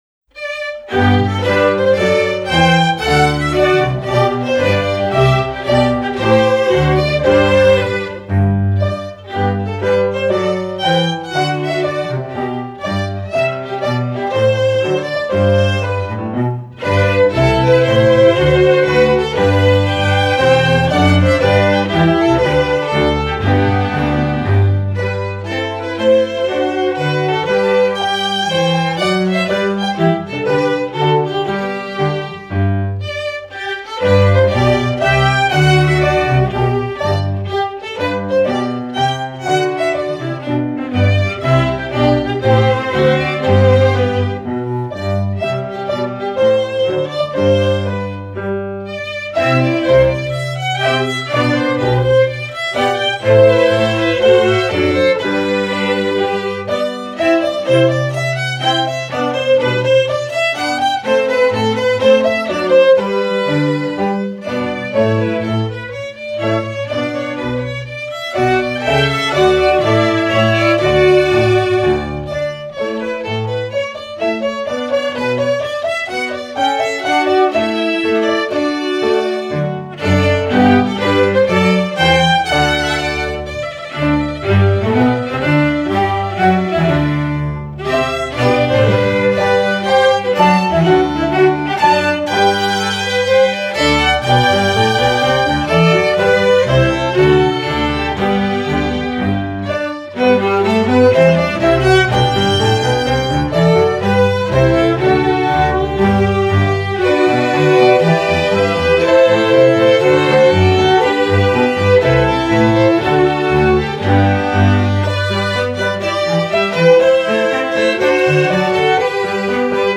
concert, instructional